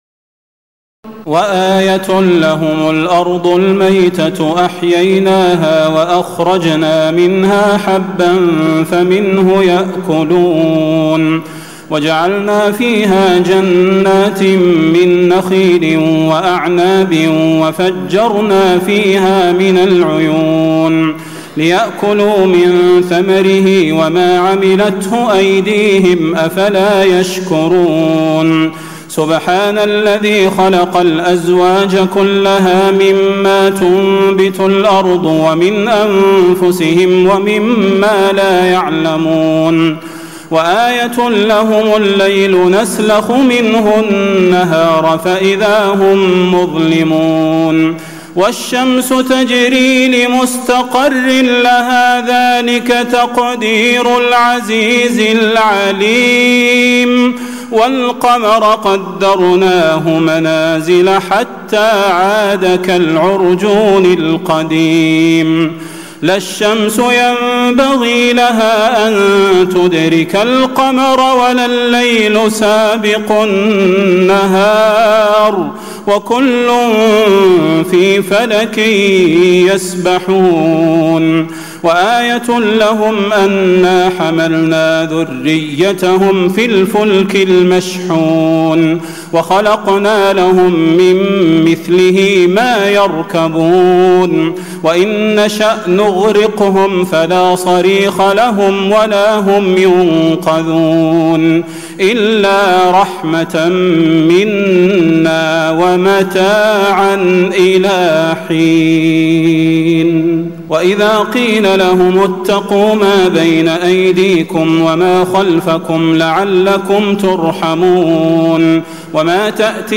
تراويح ليلة 22 رمضان 1436هـ من سور يس (33-83) والصافات (1-138) Taraweeh 22 st night Ramadan 1436H from Surah Yaseen and As-Saaffaat > تراويح الحرم النبوي عام 1436 🕌 > التراويح - تلاوات الحرمين